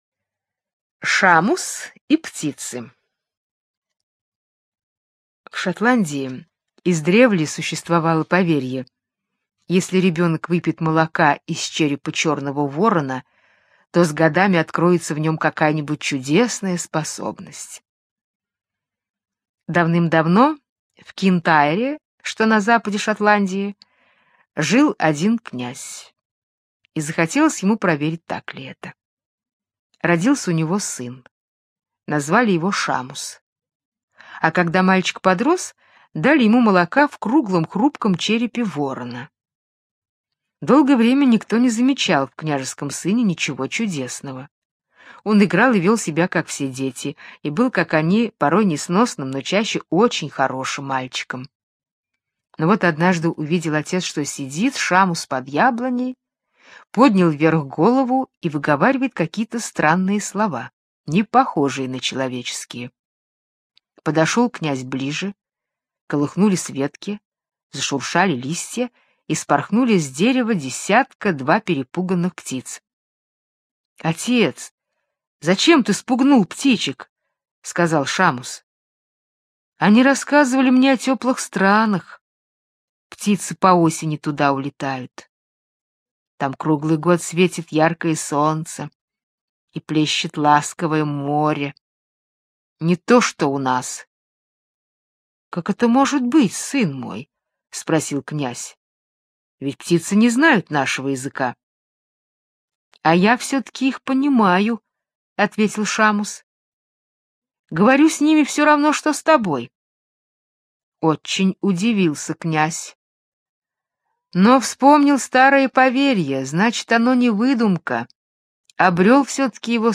Шамус и птицы - британская аудиосказка - слушать онлайн